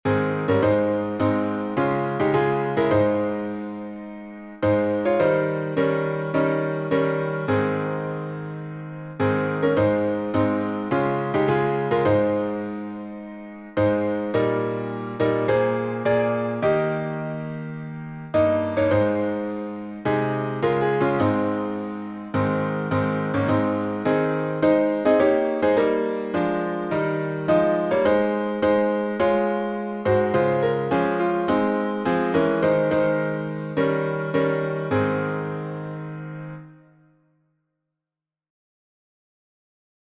Words by Eliza E. Hewitt Tune: John R. Sweney Key signature: A flat major (4 flats) Time signature: 4/4